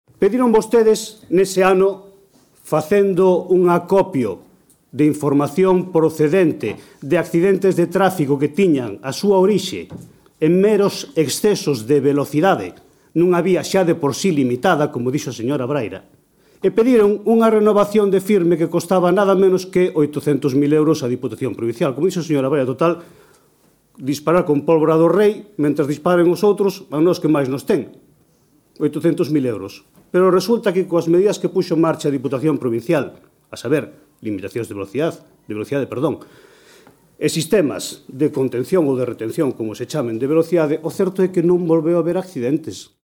O Voceiro do Goberno local, Luis Álvarez, amosou carteis do proxecto que a Diputación presentará hoxe aos medios de comunicación, no que se recollen precisamente ás obras de iluminación que reclamaban onte os populares.
PLENO-MUNICIPAL_02.mp3